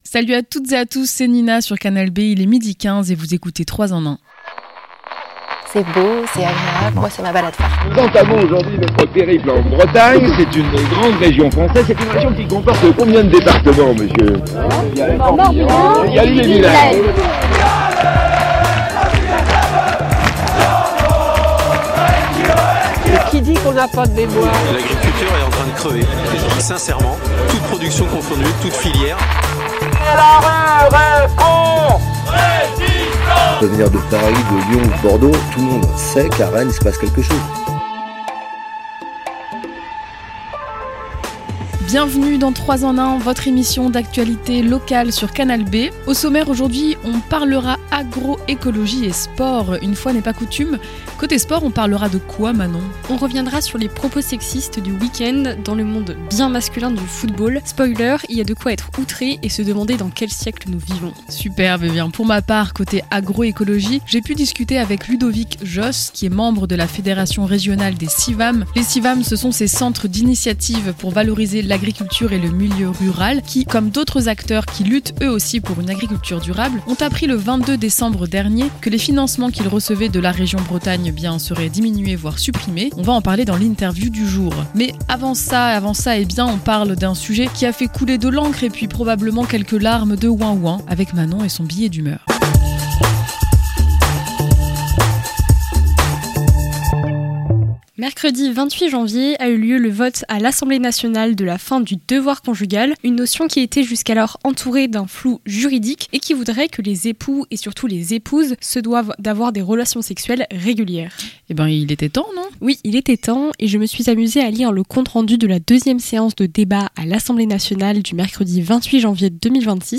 L'interview
La Chronique